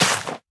Minecraft Version Minecraft Version latest Latest Release | Latest Snapshot latest / assets / minecraft / sounds / block / sweet_berry_bush / break1.ogg Compare With Compare With Latest Release | Latest Snapshot